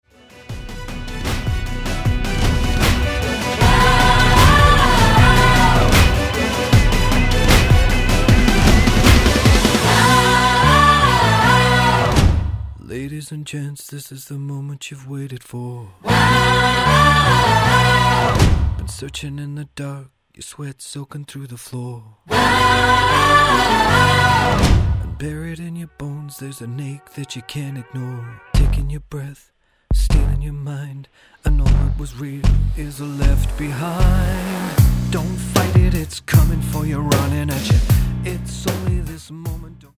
Tonart:Bm Multifile (kein Sofortdownload.
Die besten Playbacks Instrumentals und Karaoke Versionen .